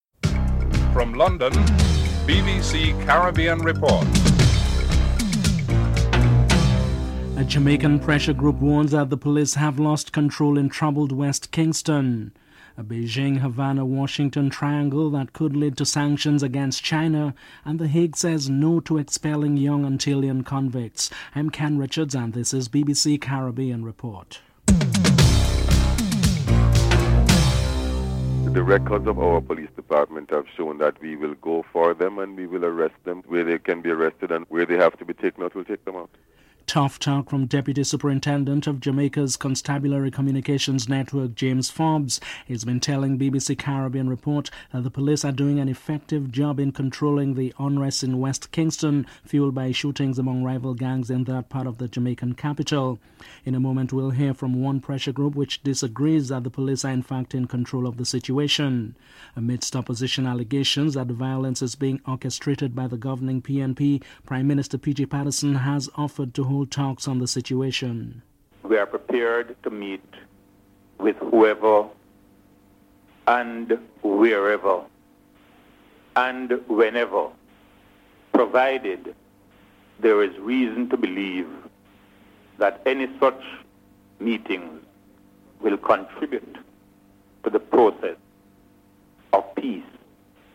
1. Headlines (00:00-00:27)
5. United States Navy today began a fresh bout of bombing practice on the Puerto Rican Island of Vieques. Admiral Kevin Greene Chief Officer of the Navy's Southern Command is interviewed (11:09-12:07)